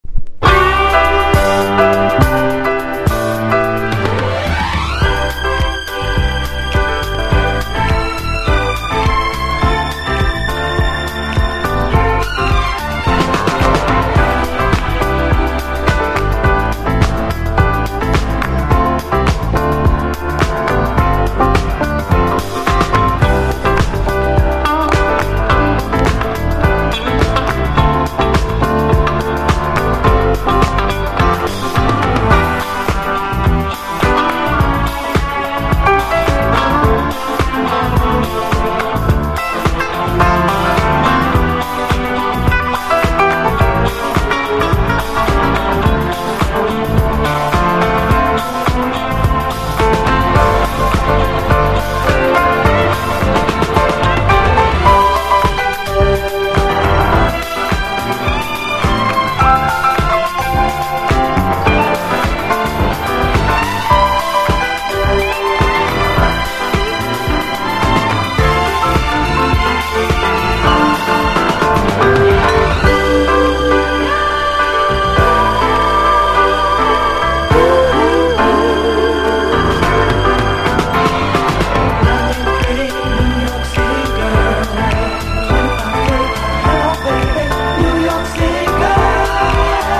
シカゴの5人組ガールズ・ソウル/ディスコ・グループ
フリーソウルファンにもおすすめ、華やかでソウルフルな名曲です。